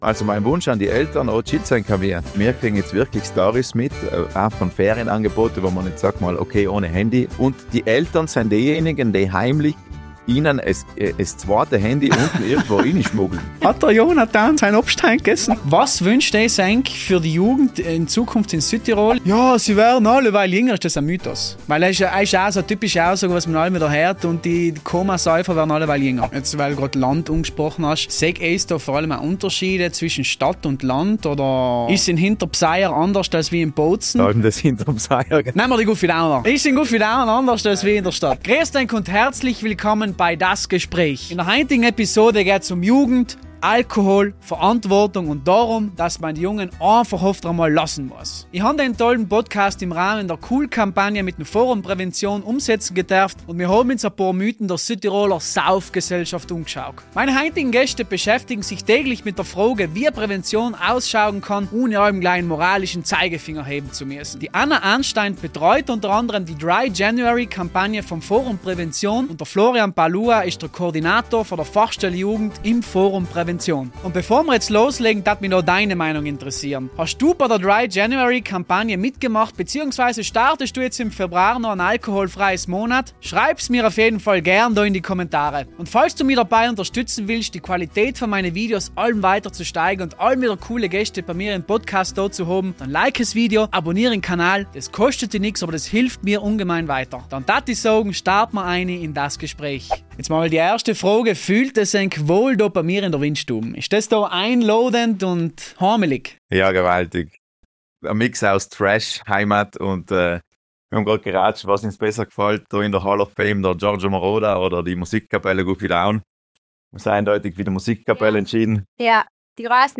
Das Gespräch